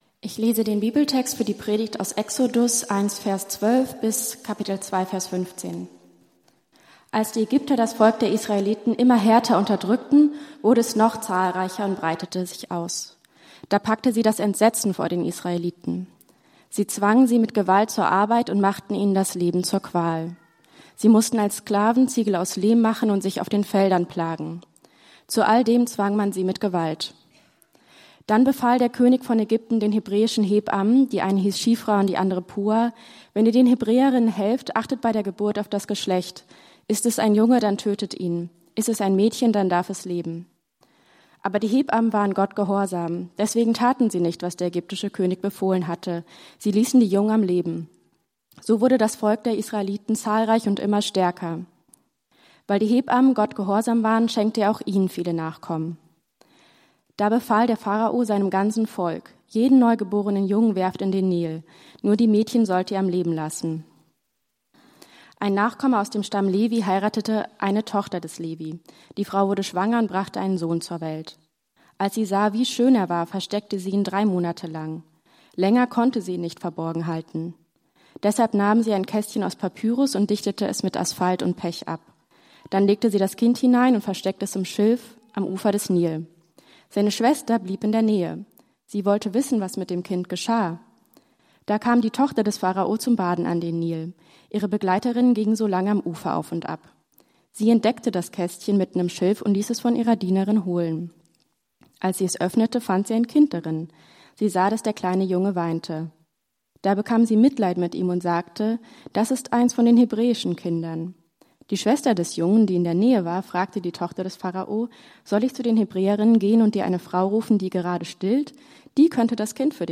Gottes Prinzipien in unserer Biografie ~ Berlinprojekt Predigten Podcast